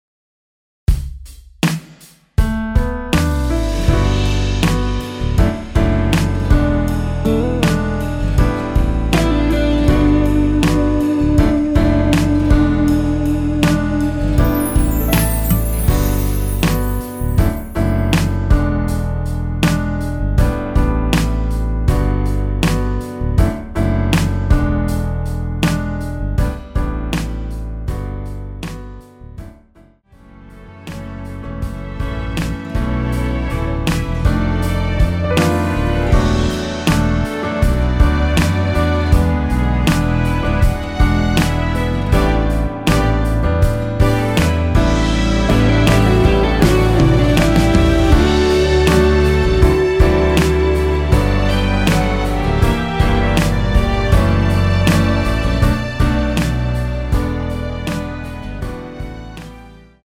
엔딩이 페이드 아웃이라 라이브 하시기 좋게 엔딩을 만들어 놓았습니다.
◈ 곡명 옆 (-1)은 반음 내림, (+1)은 반음 올림 입니다.
앞부분30초, 뒷부분30초씩 편집해서 올려 드리고 있습니다.
중간에 음이 끈어지고 다시 나오는 이유는